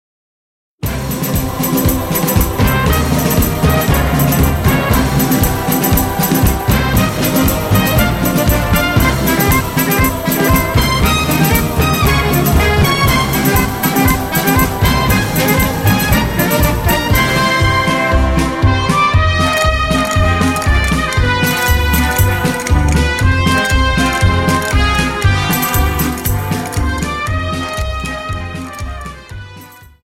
Dance: Paso Doble Song